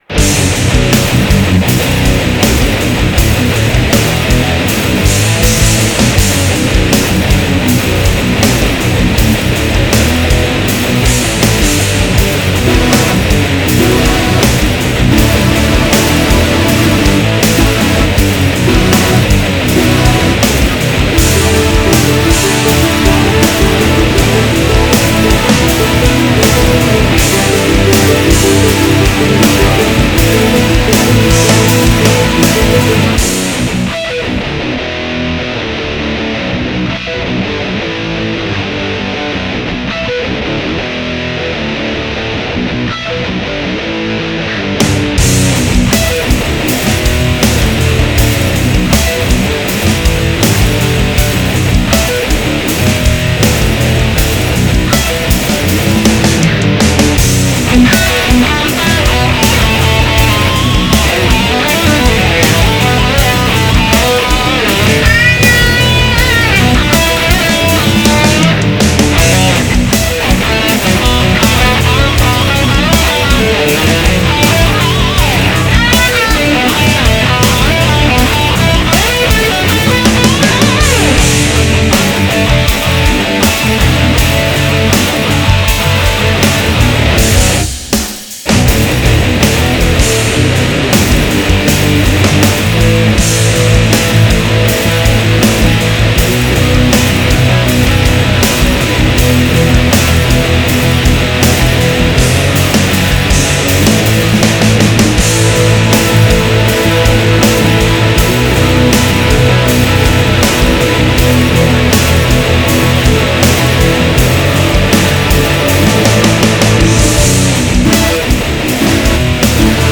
(strumentale)